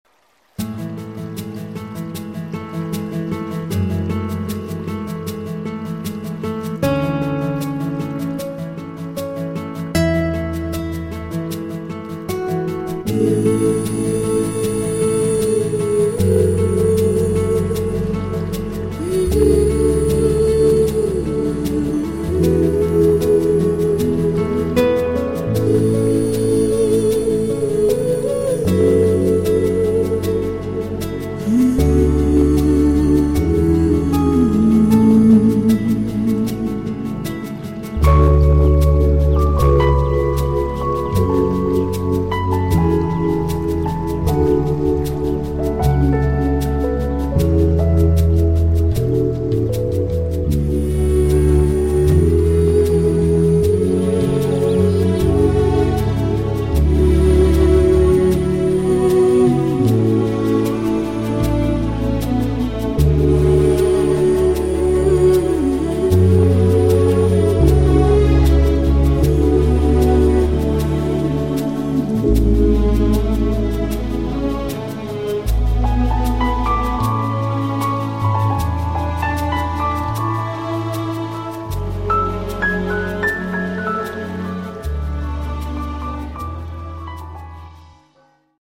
Recorded Panchathan Record Inn and AM Studios